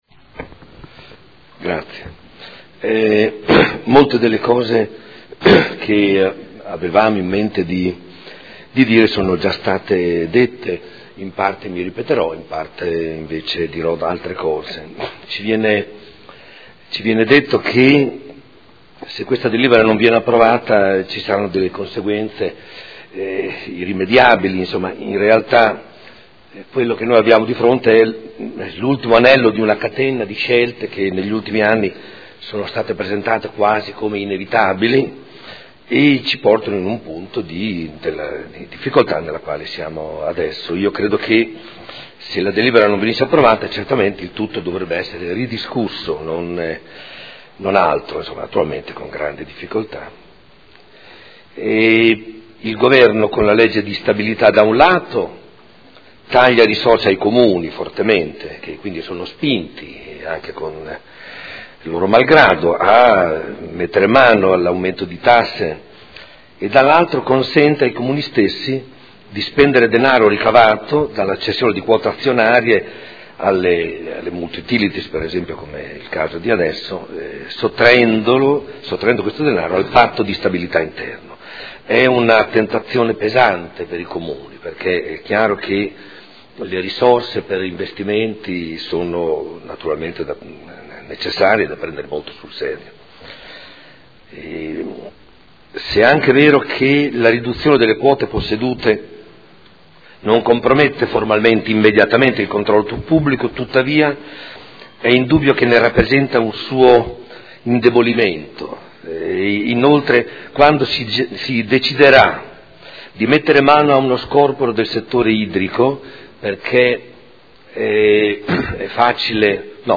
Domenico Campana — Sito Audio Consiglio Comunale